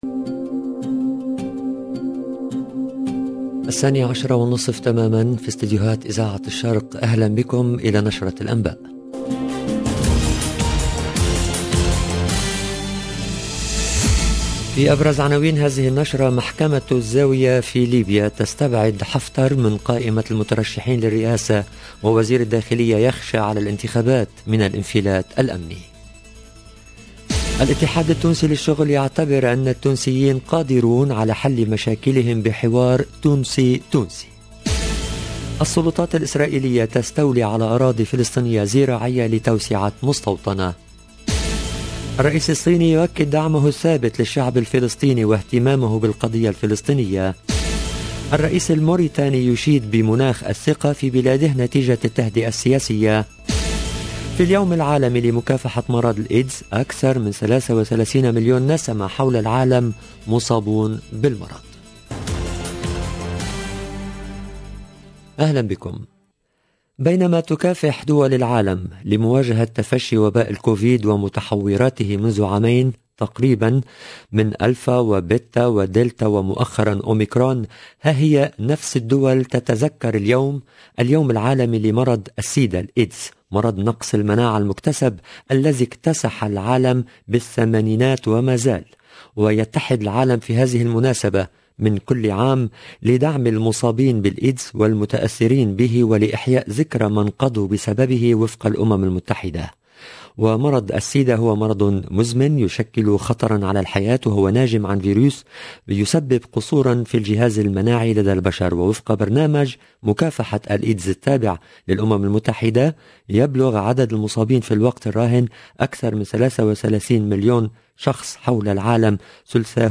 LE JOURNAL EN LANGUE ARABE DE MIDI 30 DU 1/12/21